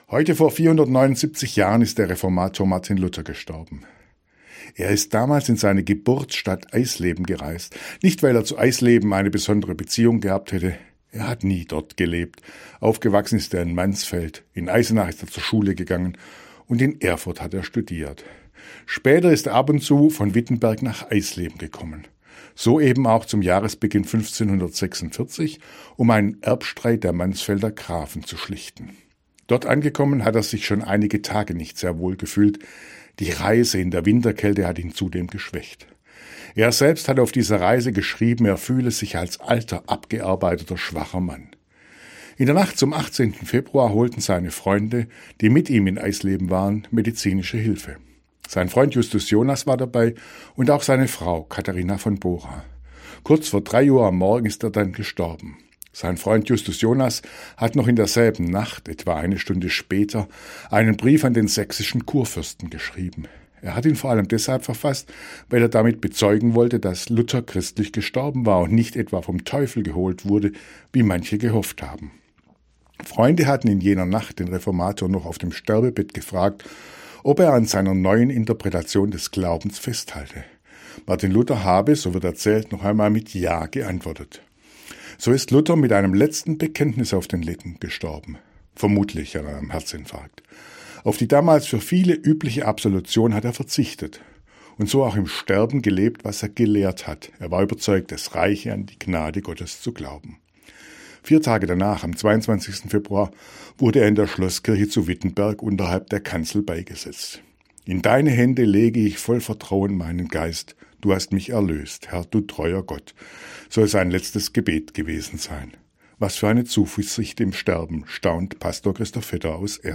Radioandacht vom 18. Februar